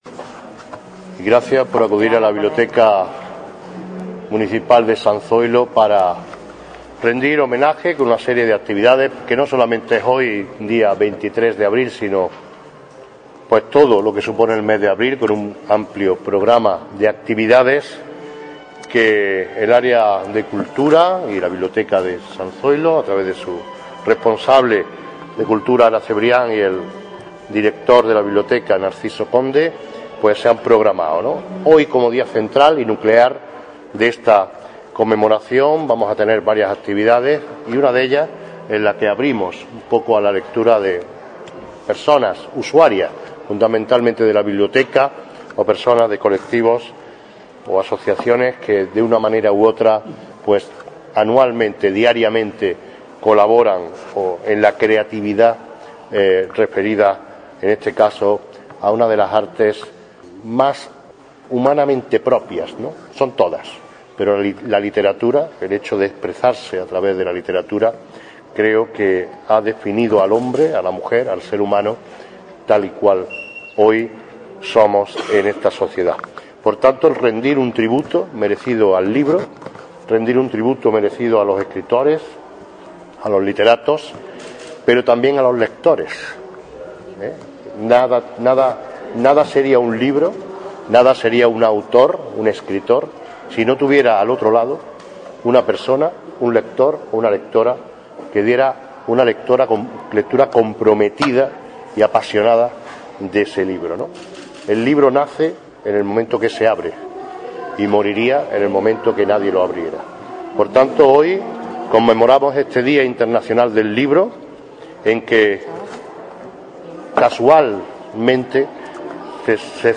Generar Pdf miércoles 23 de abril de 2014 Antequera conmemora el Día Internacional del Libro con un amplio programa de actividades durante toda la semana Generar Pdf Lectura colectiva de clásicos internacionales que ha tenido lugar en la mañana de hoy miércoles 23 de abril en el patio de la Biblioteca de San Zoilo, habiendo sido comenzada simbólicamente por el alcalde de Antequera, Manolo Barón, y la teniente de alcalde de Cultura, Festejos y Comunicación.
Cortes de voz M. Barón 860.6 kb Formato: mp3